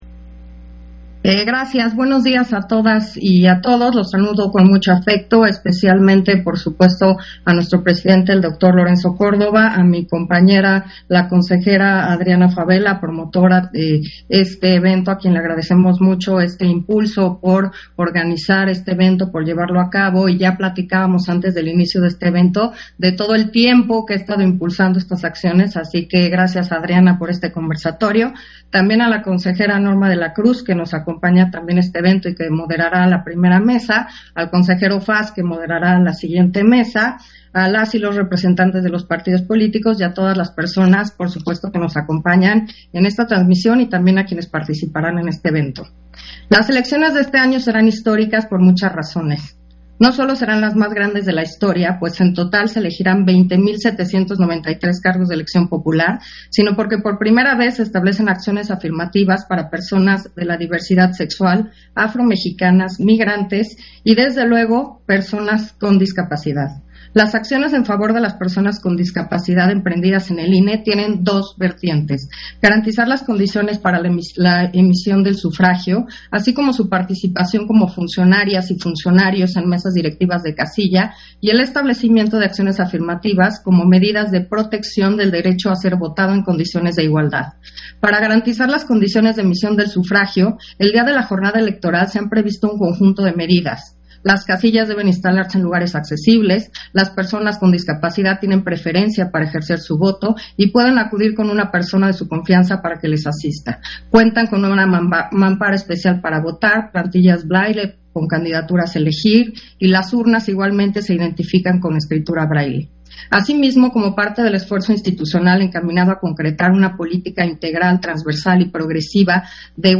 Intervención de Carla Humphrey, en la inauguración del Conversatorio de la Acciones Afirmativas a la Acción